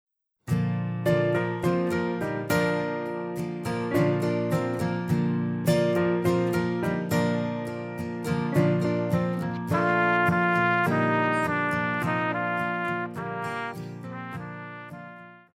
Pop
Trumpet
Band
Instrumental
Rock,Country
Only backing